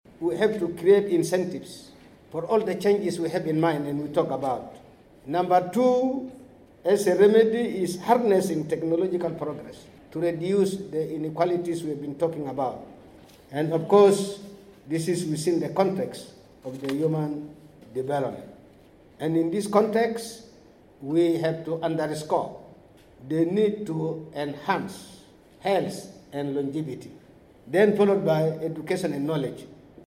He was speaking at the launch of the Global Human Development Report for this year 2020 in Juba on Wednesday.